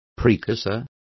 Complete with pronunciation of the translation of precursor.